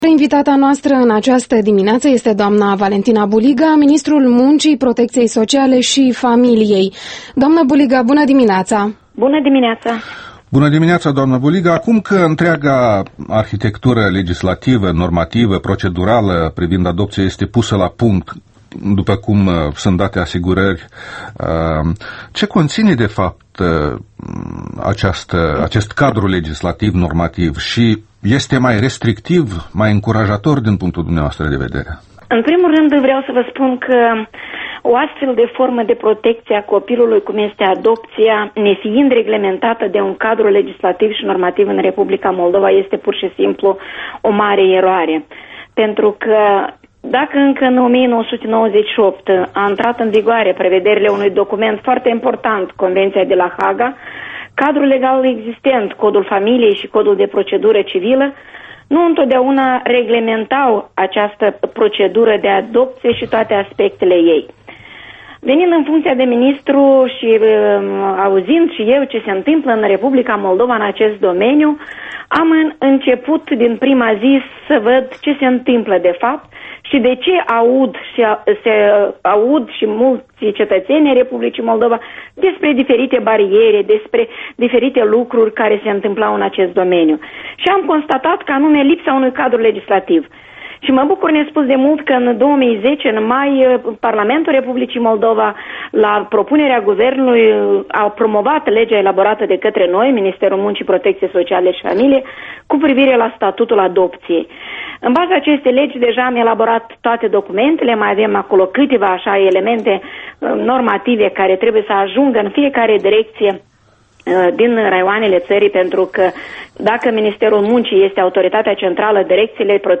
Interviul matinal la Europa Liberă: despre adopții cu Valentina Buliga, ministrul muncii